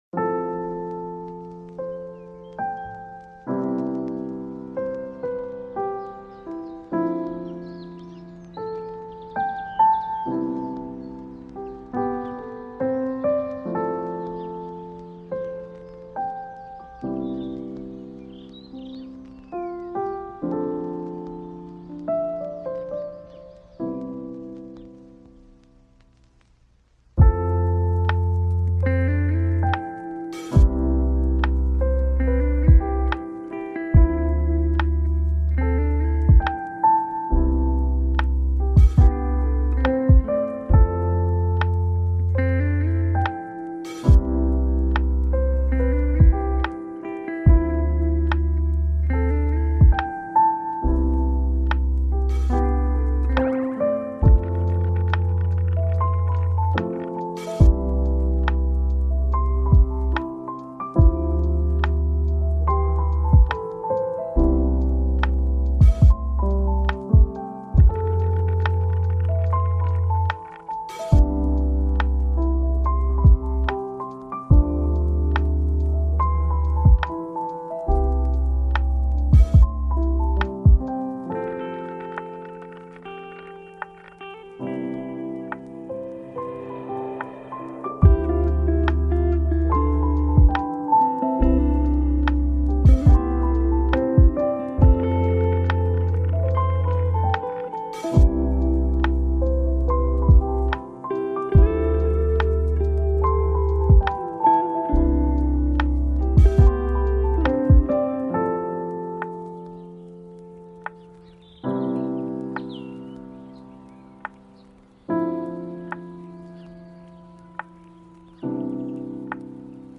Session d’Étude Café 1h